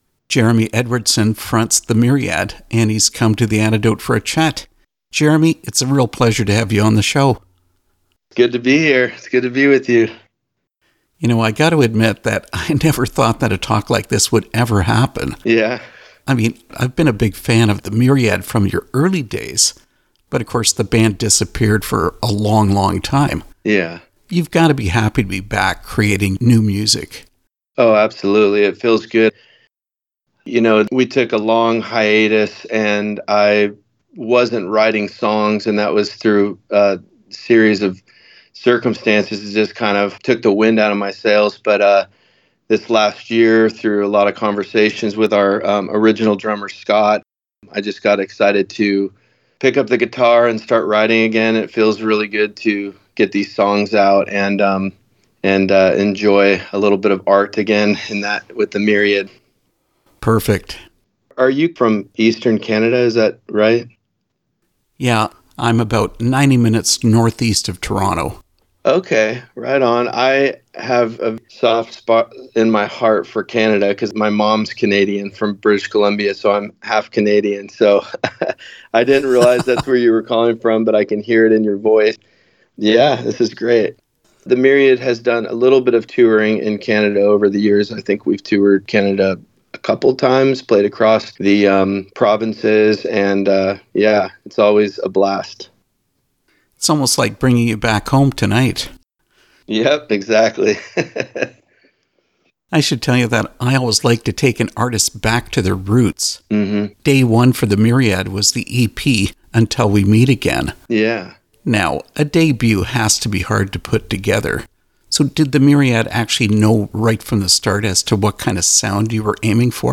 Interview with The Myriad
the-myriad-interview.mp3